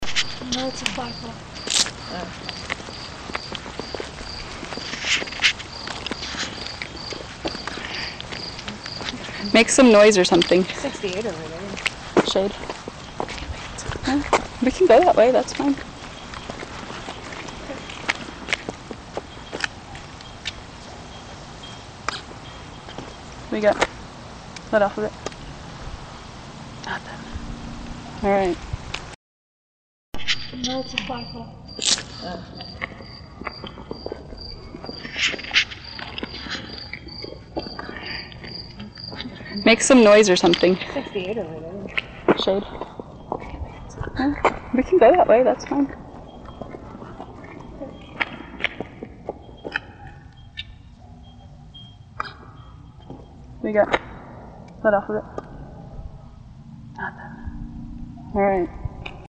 The investigation took place around 9:30 PM local time. NR stands for noise reduction in the clips below.
Bells Second - More of the illusive bells that were once again not heard by the investigators. Again, this went on for several minutes.
Bells_Second.mp3